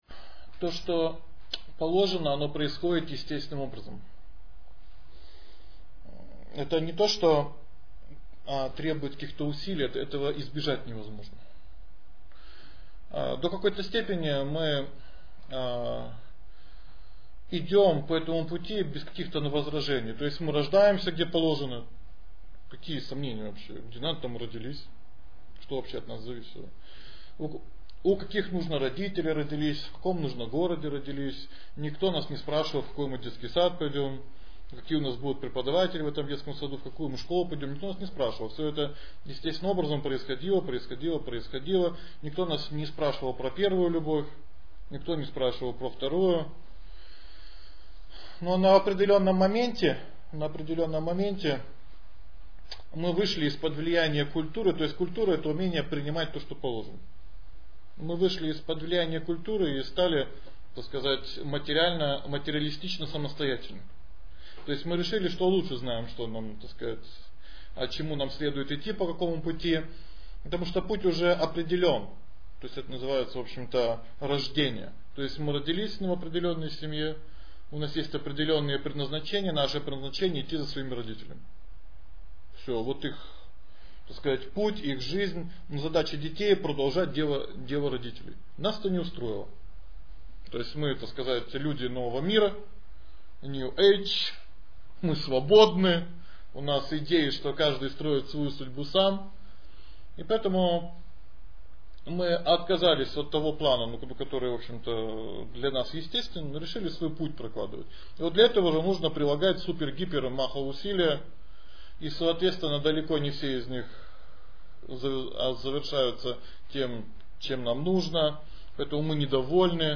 Бхагават Гита. 3 глава. Лекция 7